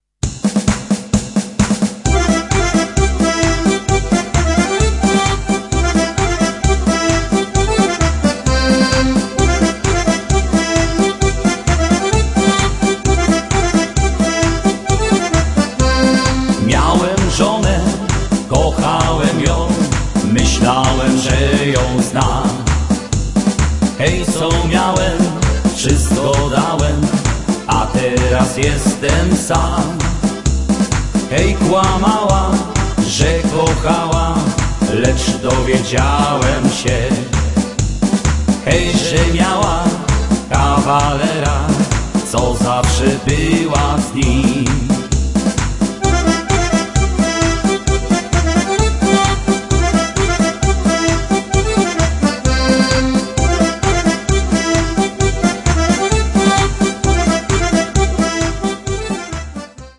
Vocals
Accordion
Percussion
Guitars